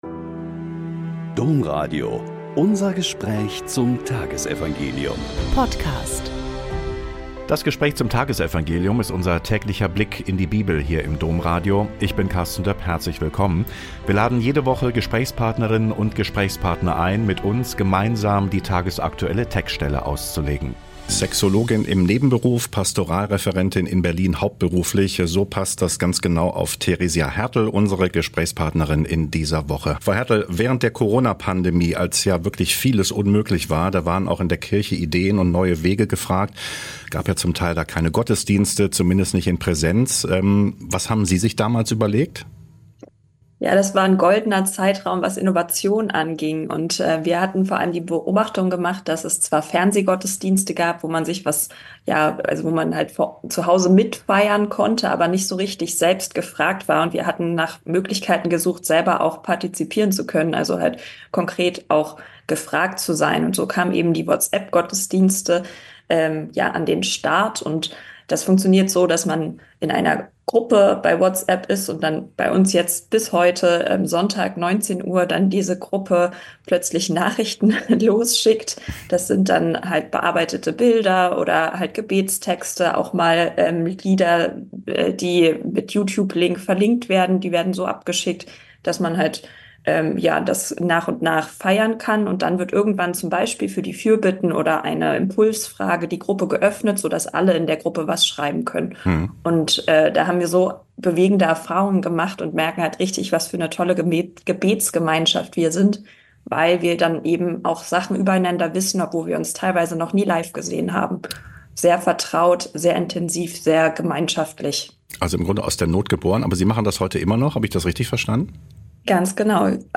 Mt 9,9-13 - Gespräch